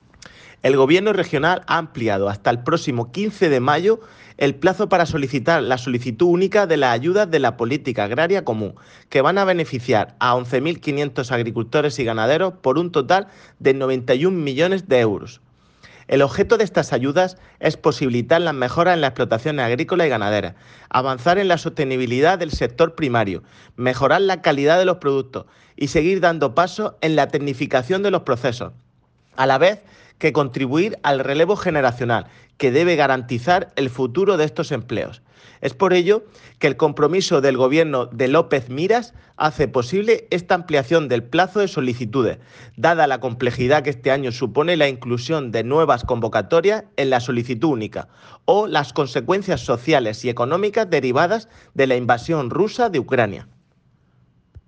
El director general de la PAC, Juan Pedro Vera, anuncia la ampliación del plazo de solicitud de las ayudas de la Política Agrícola Común hasta el 15 de mayo.